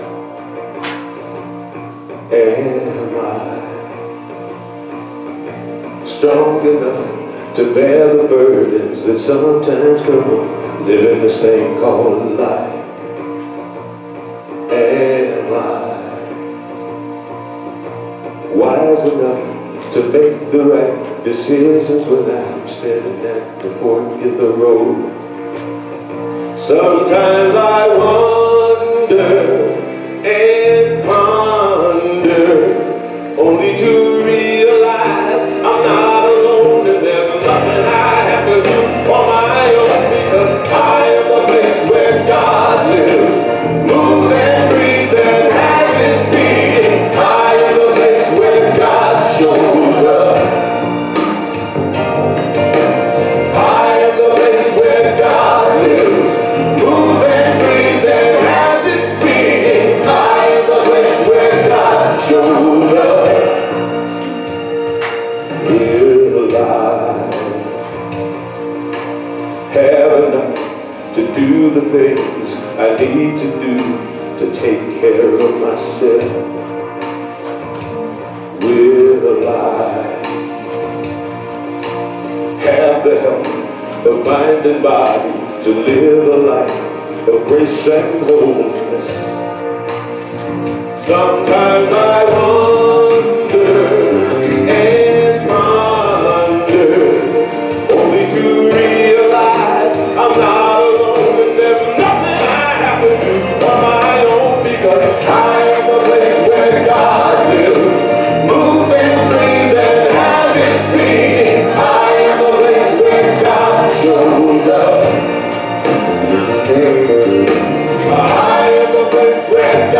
Spiritual Leader Series: Sermons 2025 Date